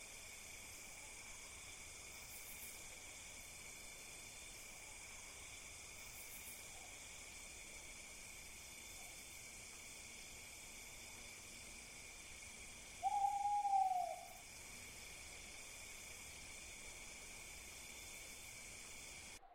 beyond/Assets/Sounds/Nature/Ambientnigh.ogg at NewStory
Ambientnigh.ogg